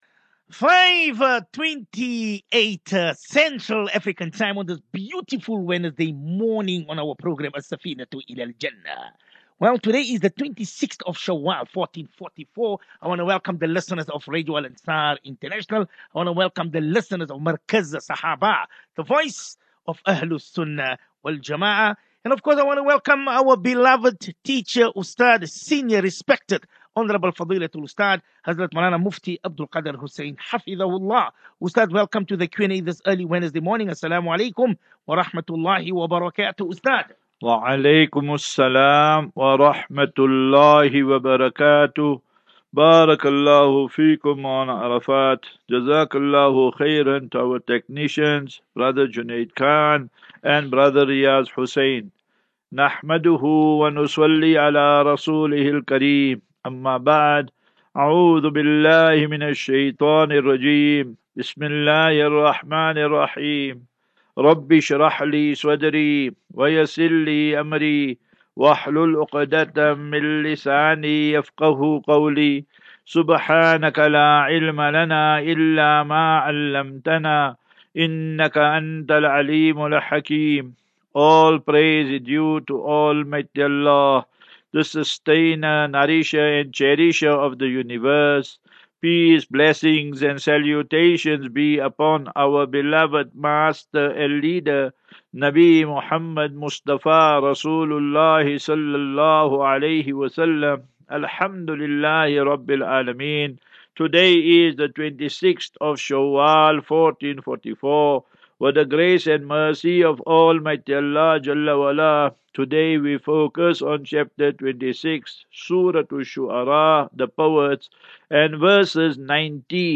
As Safinatu Ilal Jannah Naseeha and Q and A 17 May 17 May 23 Assafinatu